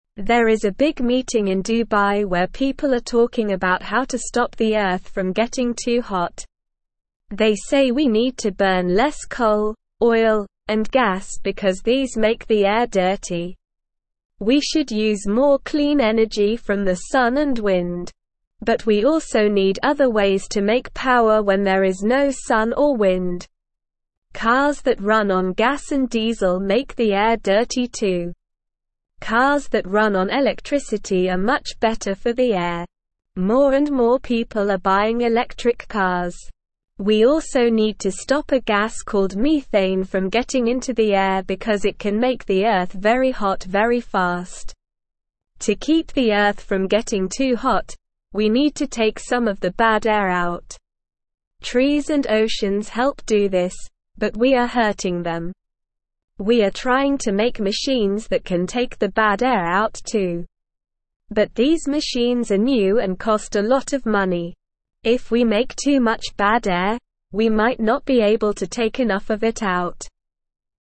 Slow
English-Newsroom-Lower-Intermediate-SLOW-Reading-Ways-to-Help-Our-Planet-and-Clean-the-Air.mp3